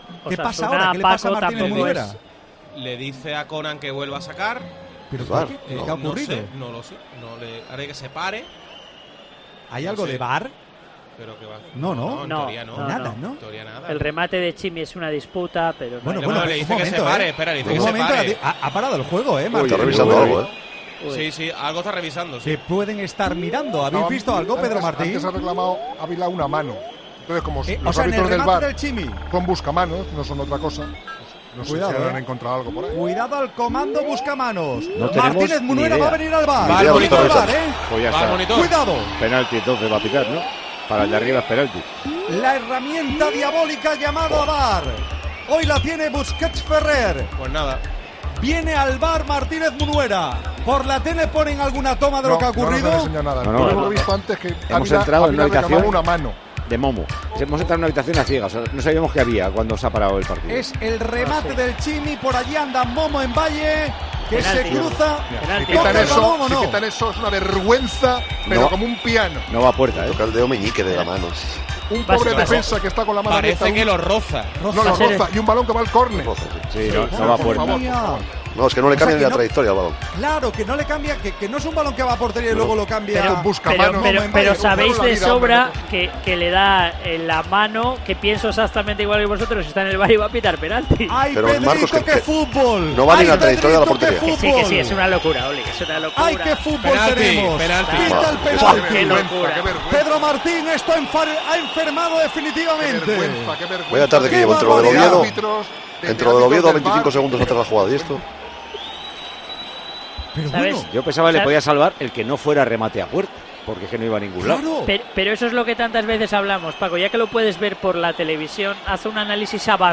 Así vivimos esta jugada en Tiempo de Juego